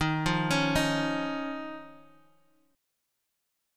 Eb7sus2 chord